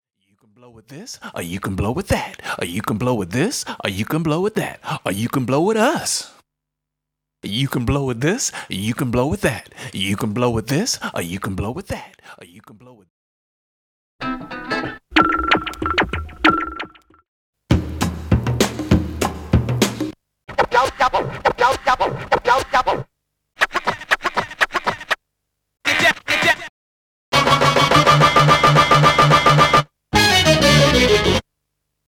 Studio Brass Hit Part
Studio Drum Loop
Studio Guitar Part 1
Studio Vocal Dual Channel Stem